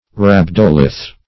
Search Result for " rhabdolith" : The Collaborative International Dictionary of English v.0.48: Rhabdolith \Rhab"do*lith\, n. [Gr.
rhabdolith.mp3